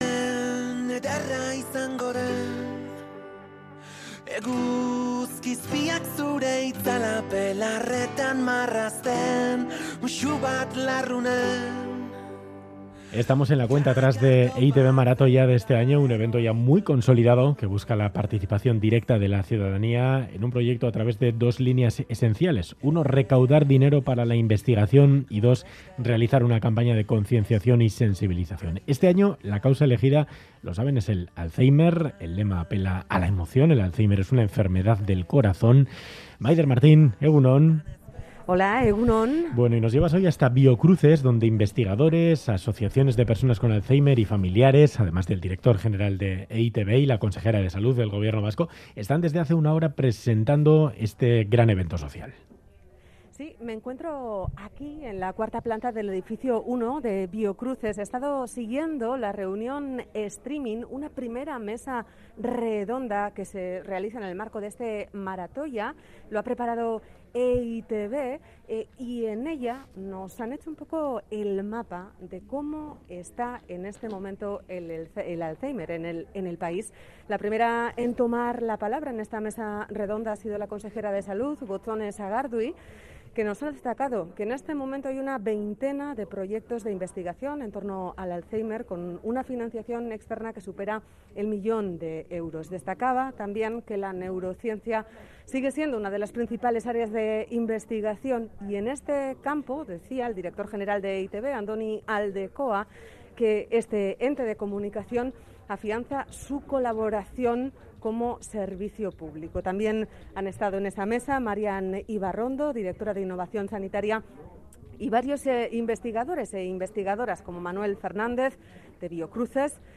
Conseguir financiación para seguir con la investigación del alzheimer es el objetivo principal de este año de EITB Maratoia. En la primera mesa redonda preparada por EITB se ha hecho el mapa de como está el alzheimer en Euskadi.